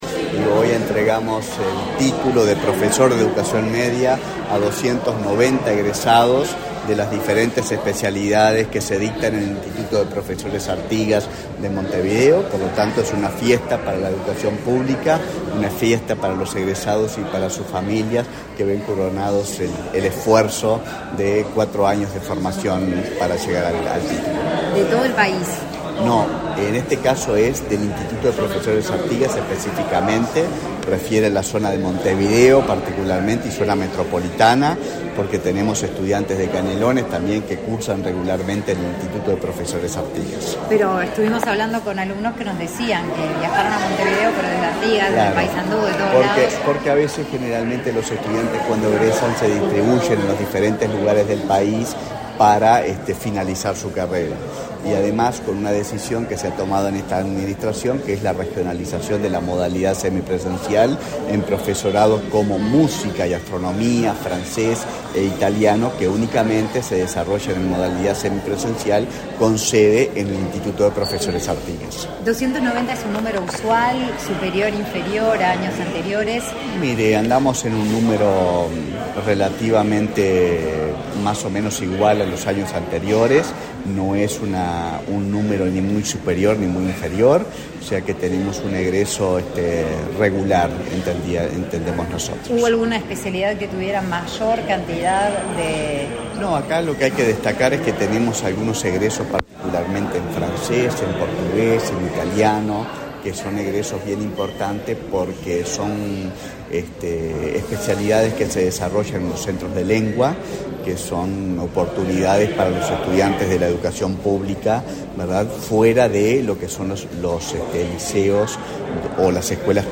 Declaraciones a la prensa del presidente del CFE, Víctor Pizzichillo
En la oportunidad, el presidente del Consejo de Formación en Educación (CFE), Víctor Pizzichillo, realizó declaraciones a la prensa.